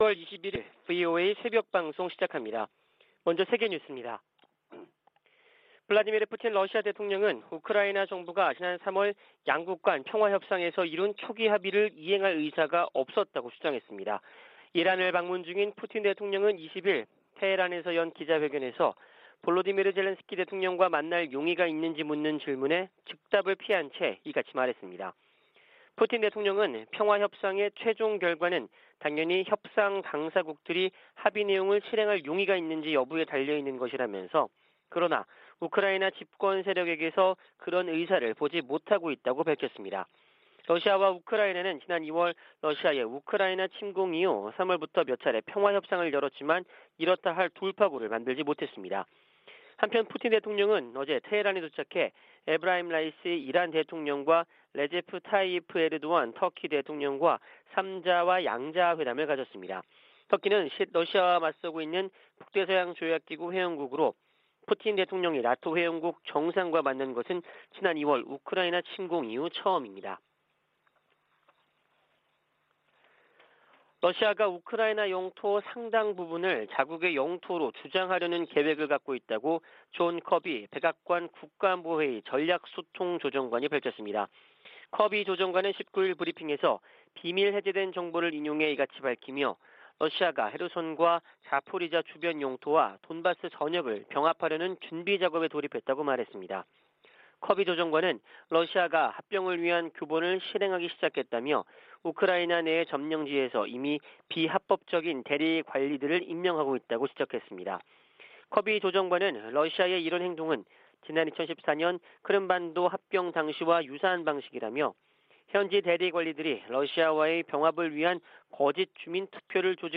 VOA 한국어 '출발 뉴스 쇼', 2022년 7월 21일 방송입니다. 미 국무부 ‘2022 인신매매 실태 보고서’에서 북한이 20년 연속 최악의 인신매매 국가로 지목됐습니다.